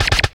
07.4 LASER.wav